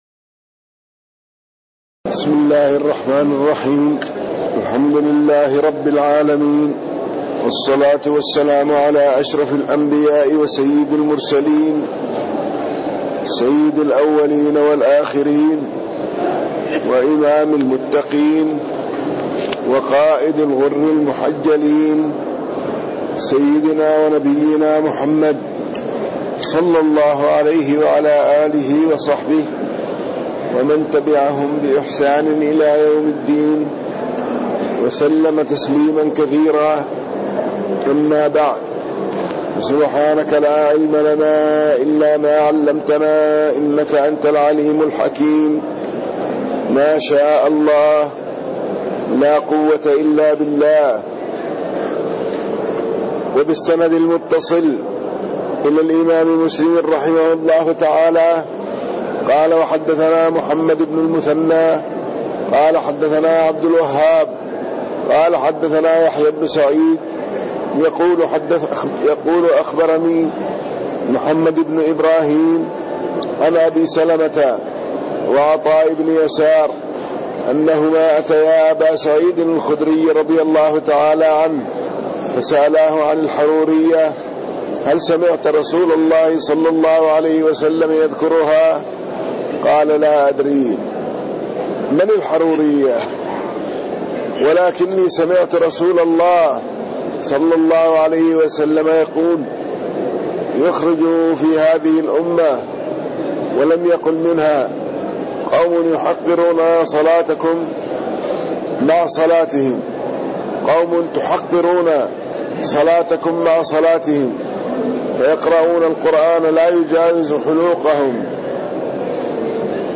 تاريخ النشر ٥ محرم ١٤٣٣ هـ المكان: المسجد النبوي الشيخ